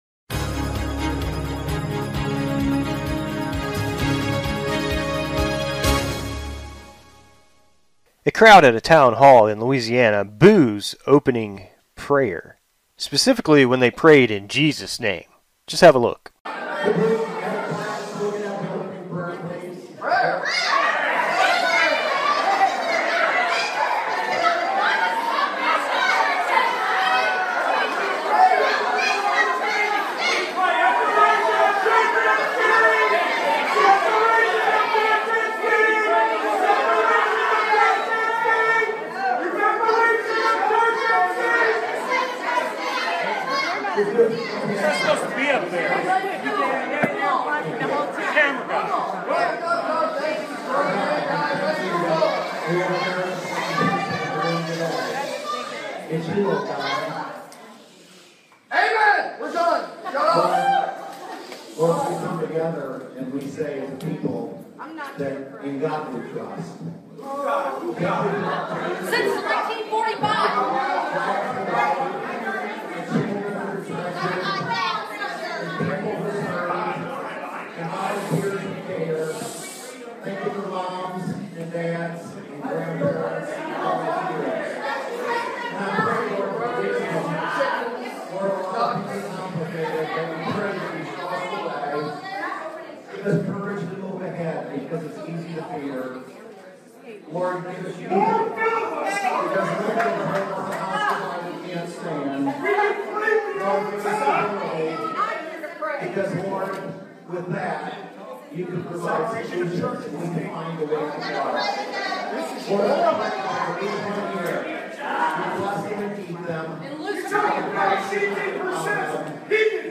Crowd Boos The Name Of Jesus After Opening Prayer
Crowd_Boos_The_Name_Of_Jesus_After_Opening_Prayer.mp3